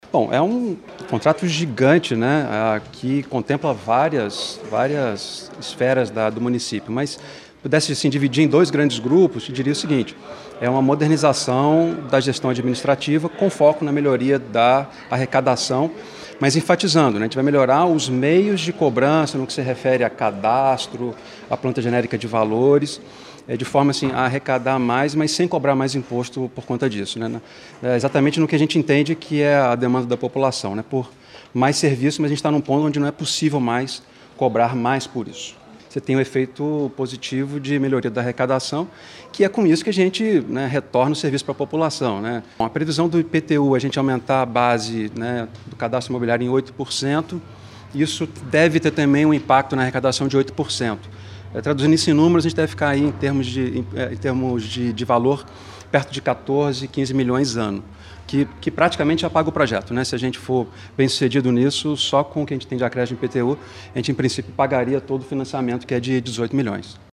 É o explica o secretário de Planejamento e Gestão, Lúcio Sá Fortes.